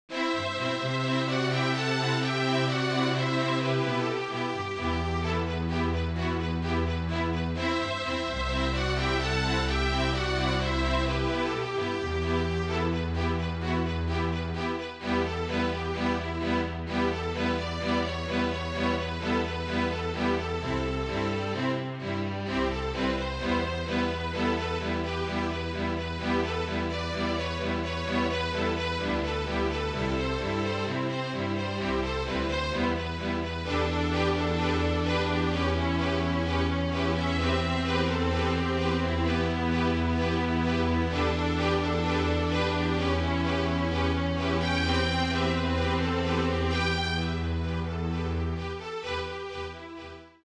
FLUTE QUARTET
(Flute, Violin, Viola and Cello)
MIDI